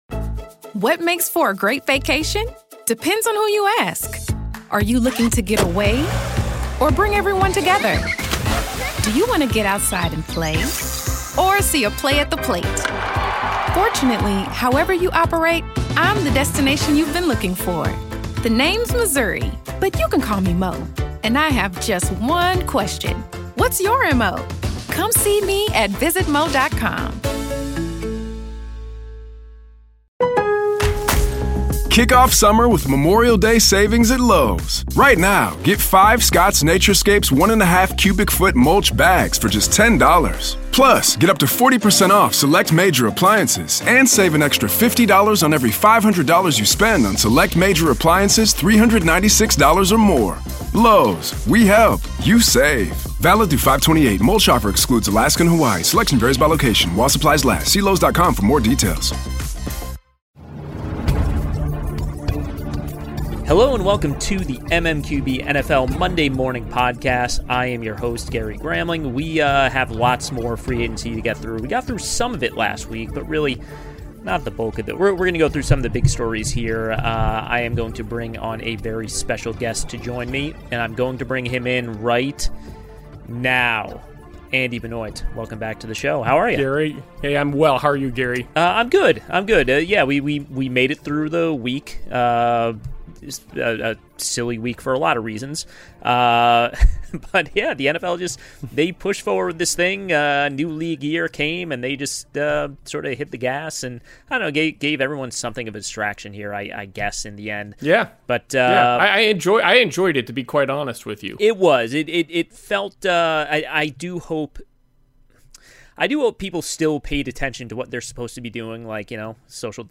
special guest